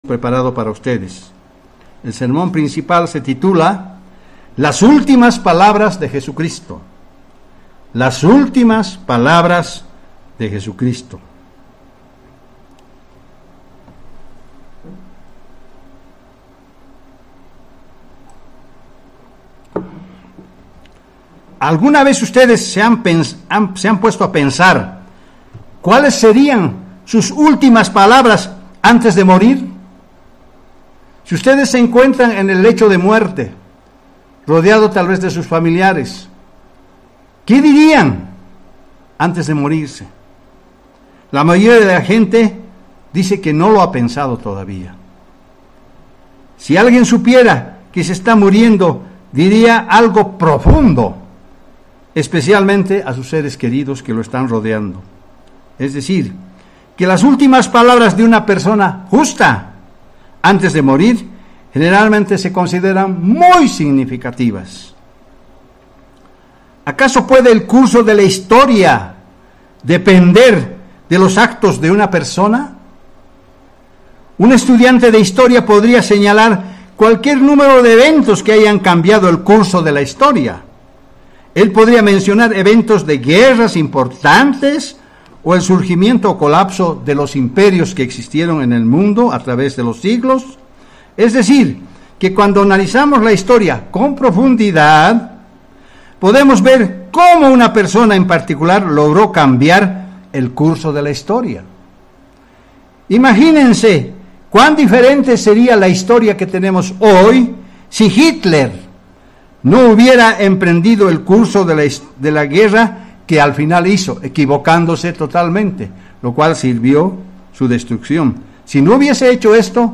Sermones
Given in La Paz